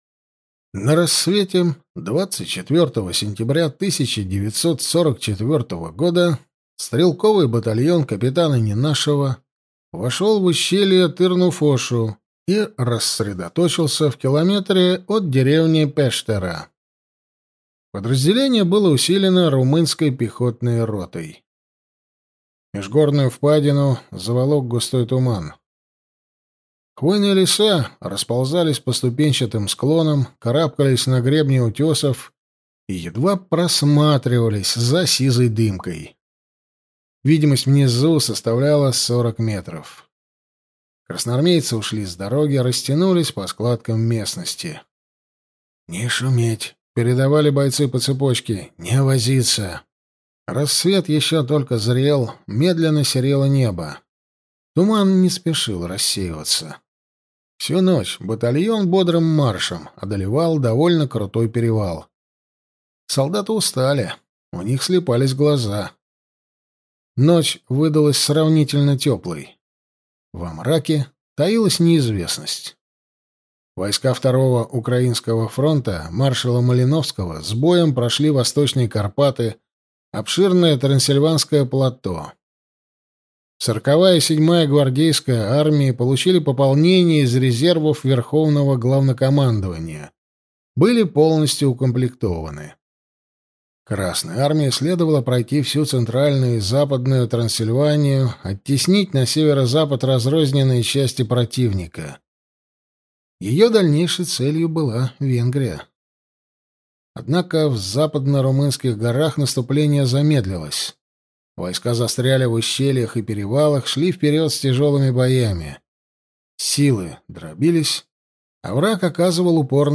Аудиокнига Башни немецкого замка | Библиотека аудиокниг